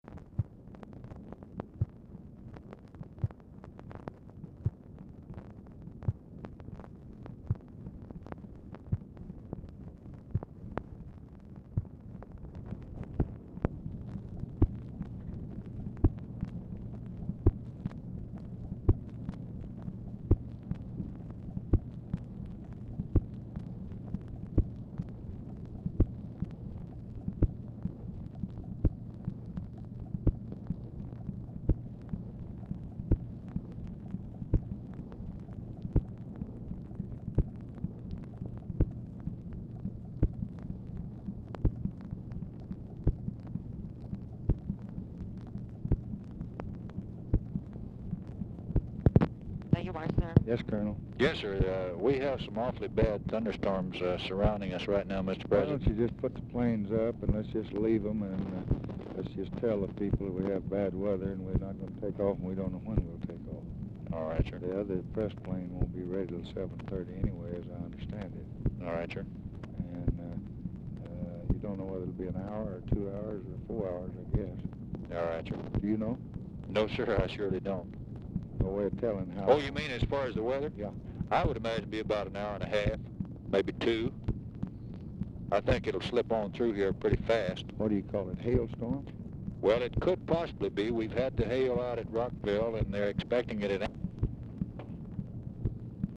LBJ ON HOLD 0:50; RECORDING ENDS BEFORE CONVERSATION IS OVER
Format Dictation belt
Location Of Speaker 1 Oval Office or unknown location
Specific Item Type Telephone conversation